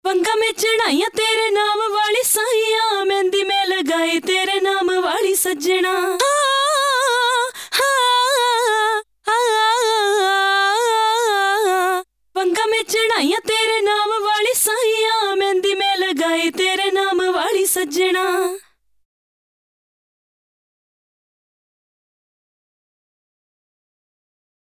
how i can make vocal like these?? which effect is used to make these wide